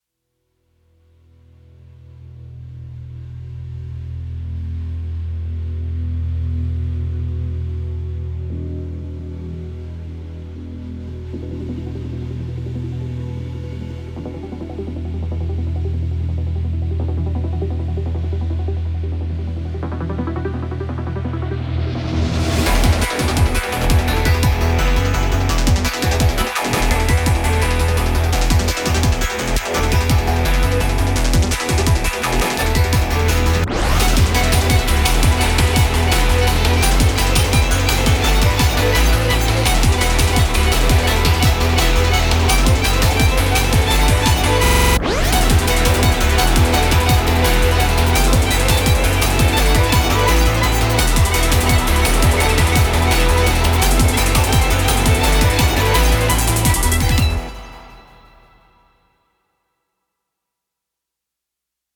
———————————————— Production Music Examples ————————————————